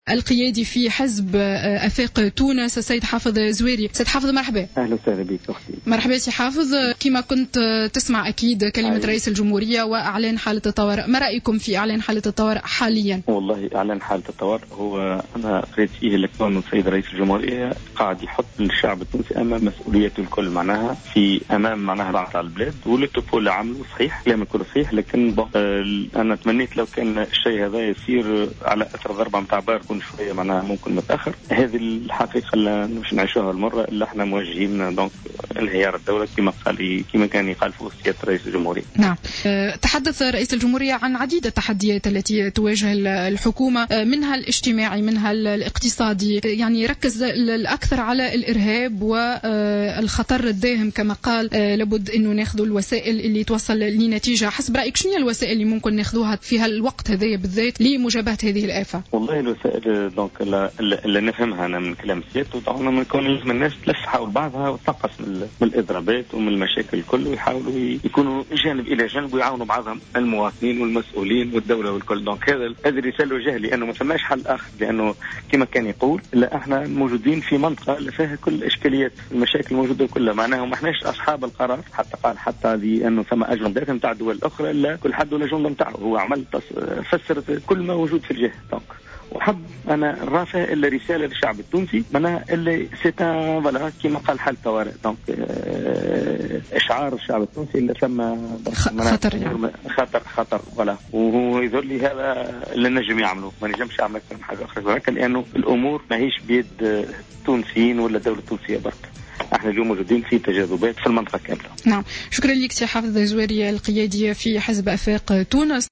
اعتبر حافظ الزواري القيادي في حزب آفاق تونس في تصريح لجوهرة أف أم اليوم السبت 04 جويلية 2015 أن قرار رئيس الجمهورية إعلان حالة الطوارئ هو قرار صائب و لكنه متأخر وكان يجب أن يكون إثر عملية باردو الإرهابية.